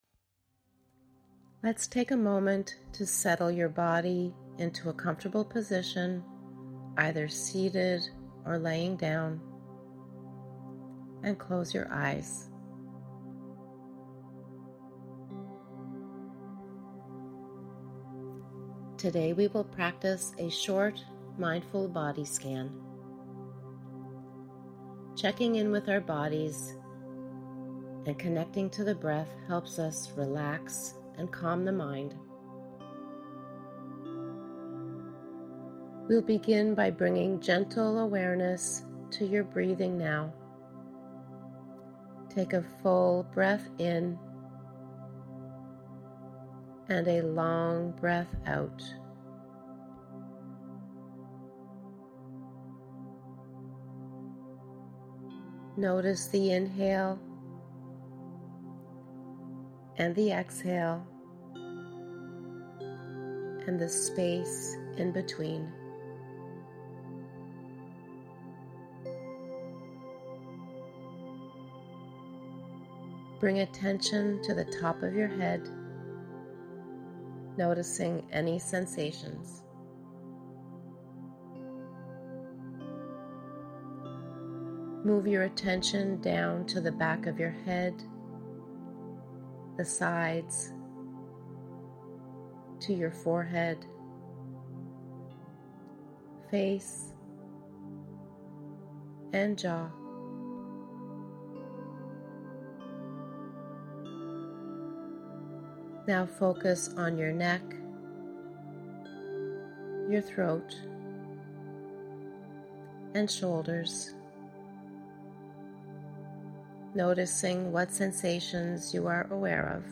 Bodyscan.mp3